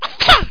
Sneeze.mp3